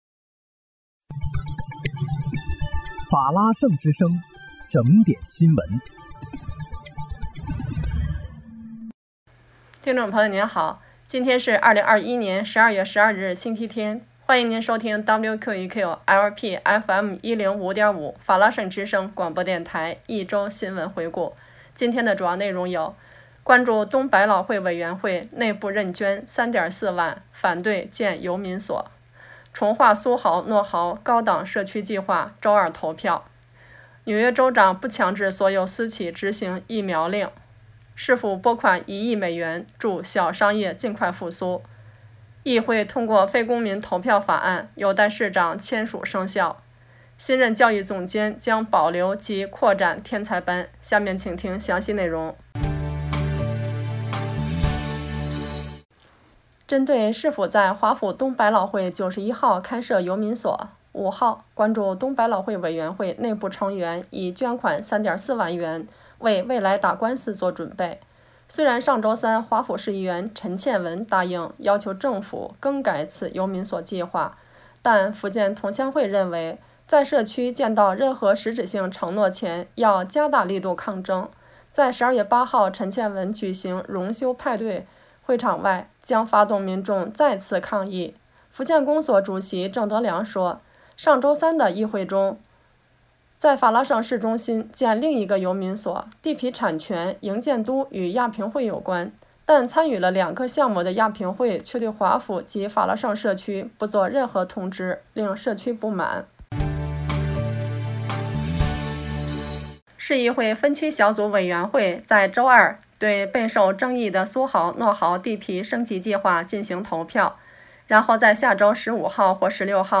12月12日（星期日）一周新闻回顾